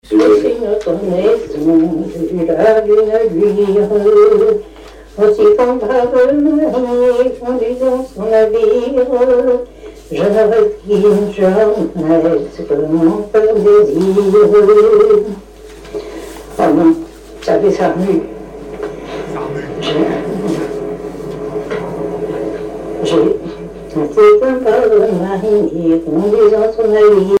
Mémoires et Patrimoines vivants - RaddO est une base de données d'archives iconographiques et sonores.
Chansons et commentaires
Pièce musicale inédite